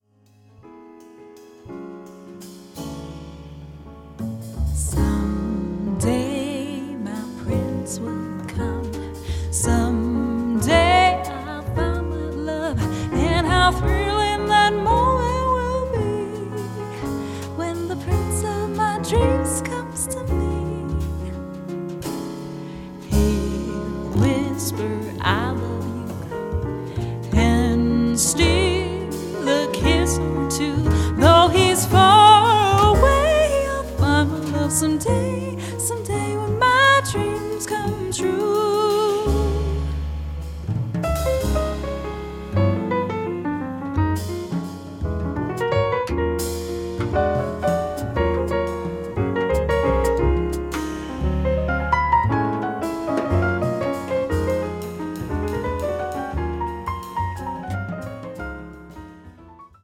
voc
piano
bass
drums